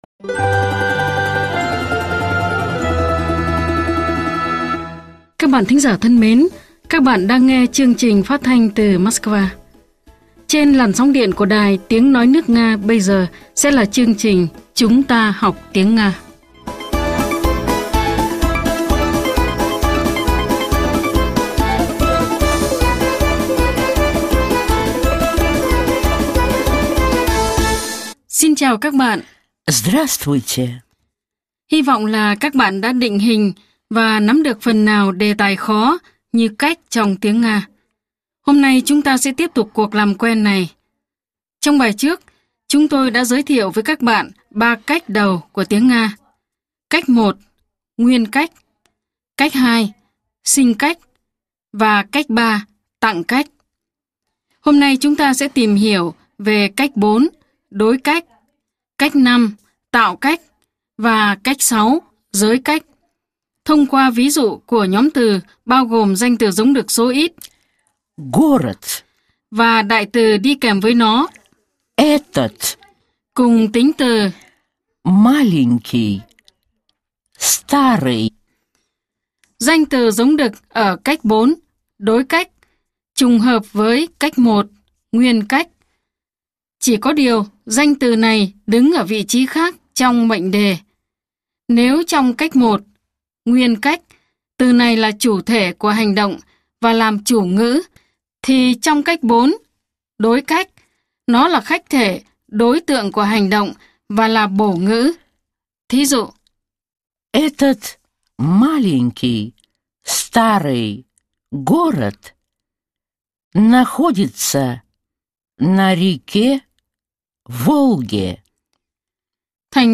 Bài 14 – Bài giảng tiếng Nga - Tiếng Nga cho mọi người